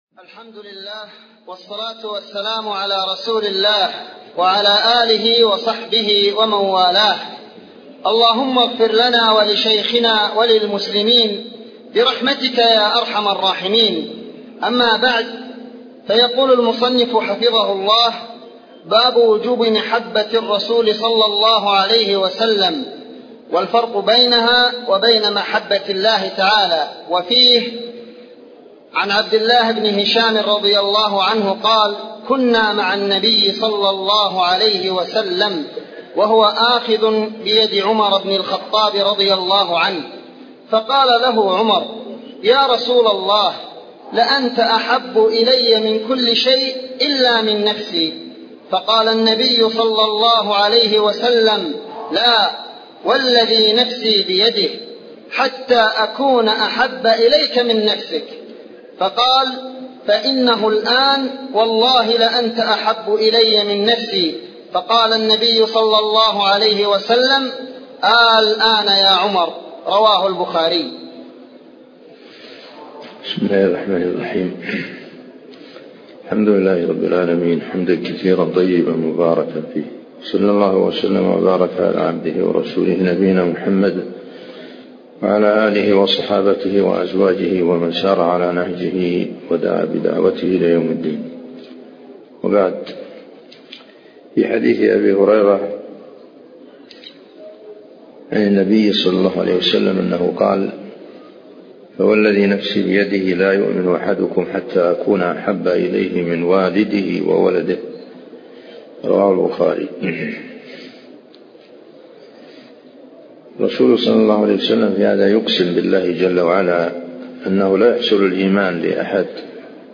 تفاصيل المادة عنوان المادة الدرس (41) شرح المنهج الصحيح تاريخ التحميل الأحد 15 يناير 2023 مـ حجم المادة 28.43 ميجا بايت عدد الزيارات 266 زيارة عدد مرات الحفظ 116 مرة إستماع المادة حفظ المادة اضف تعليقك أرسل لصديق